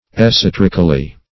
esoterically - definition of esoterically - synonyms, pronunciation, spelling from Free Dictionary Search Result for " esoterically" : The Collaborative International Dictionary of English v.0.48: Esoterically \Es`o*ter"ic*al*ly\, adv.